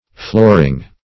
Flooring \Floor"ing\, n.